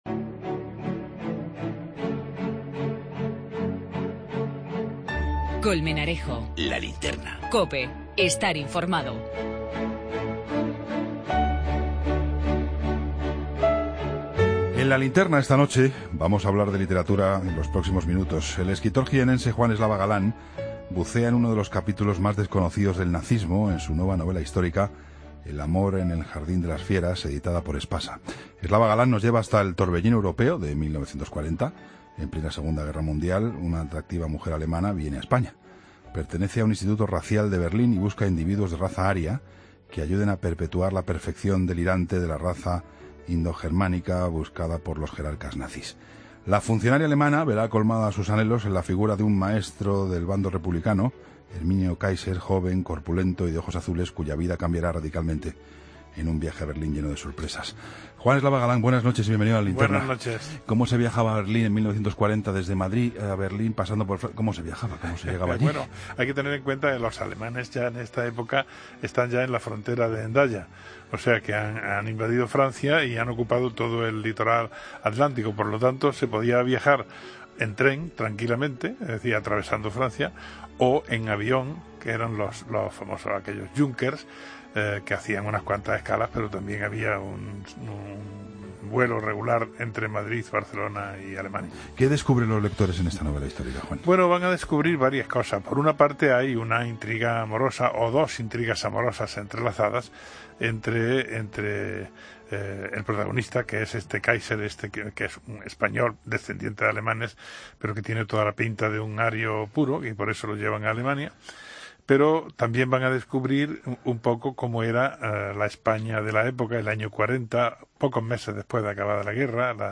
Entrevista a Juan Eslava Galán